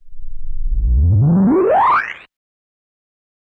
K-7 FX Rise.wav